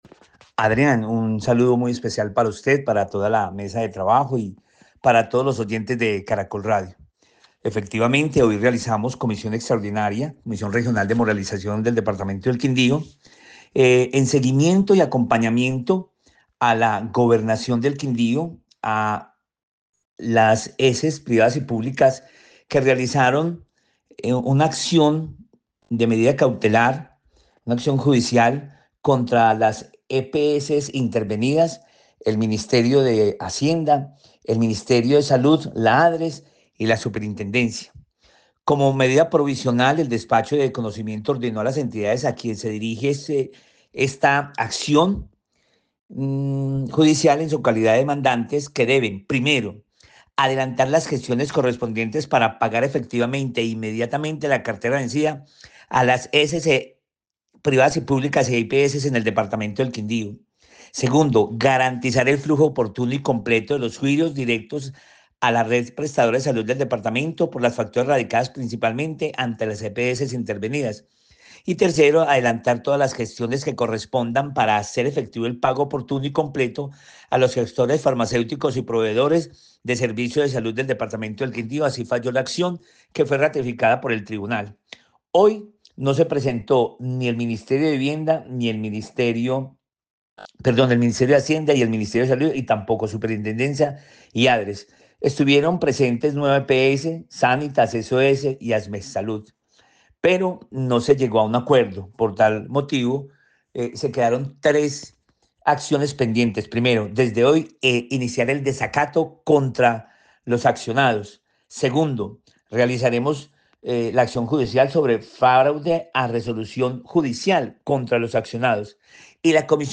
El gerente de la Contraloría en el Quindío en diálogo con Caracol Radio indicó “realizamos comisión Regional de Moralización del departamento del Quindío, en seguimiento y acompañamiento a la gobernación del Quindío, a las IPS privadas y públicas que realizaron una acción de medida cautelar, una acción judicial contra las EPS intervenidas, el Ministerio de Hacienda, el Ministerio de Salud, la ADRES y la superintendencia.